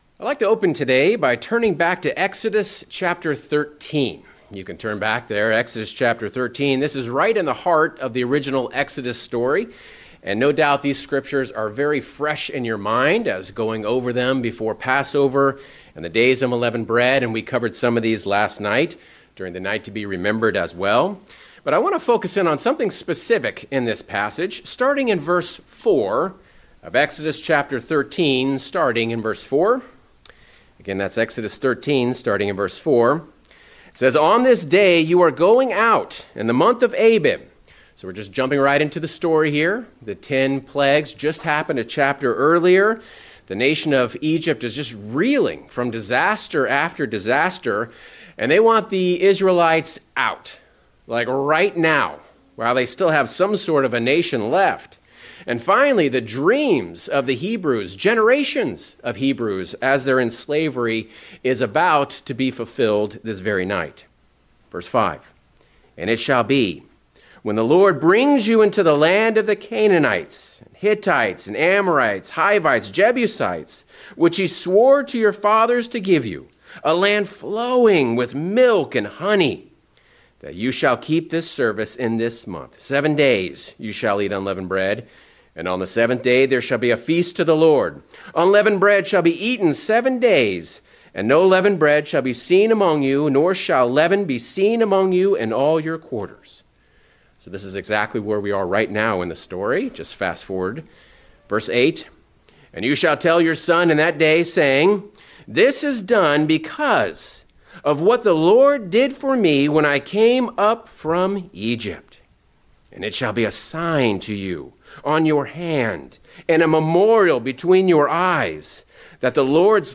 Sermons Unleavened For a Purpose
Given in Northern Virginia